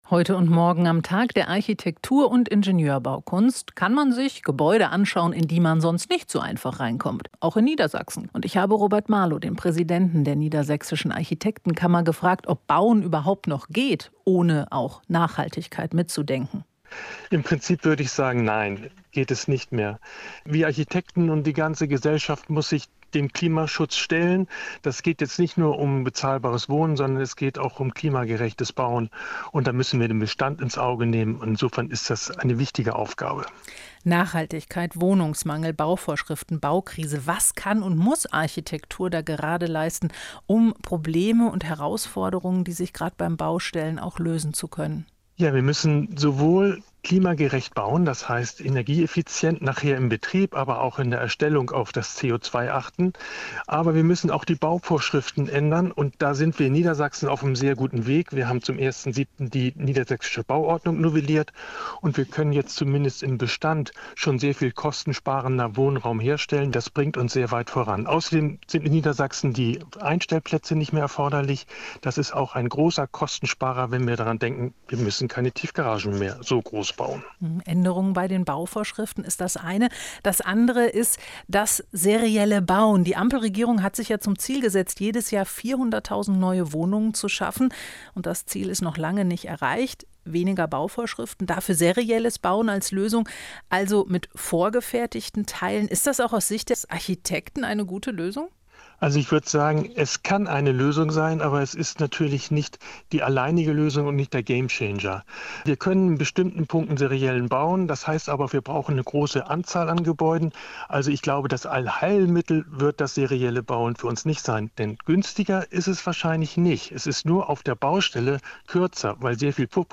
NDR-Hörfunkinterview